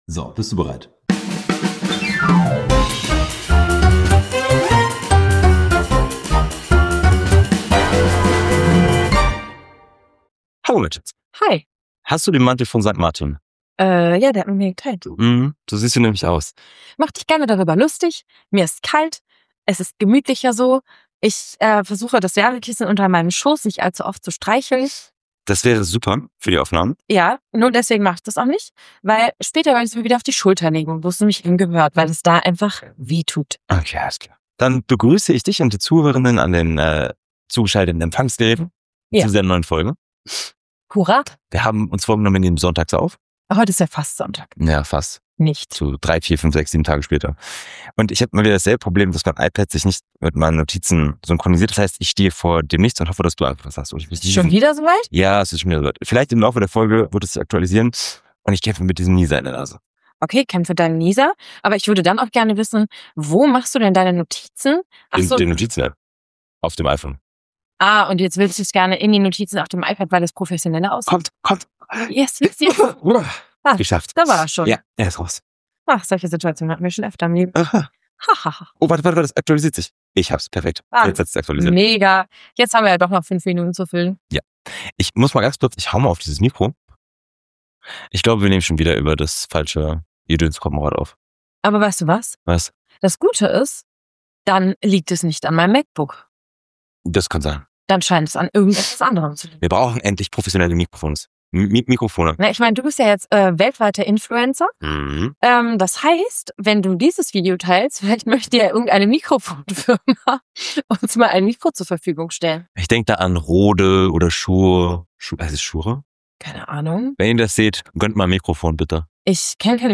Aber wir sind Revoluzer, also haben wir das Mikro umgestellt und beten zu Steve Jobs, dass nun alles besser wird.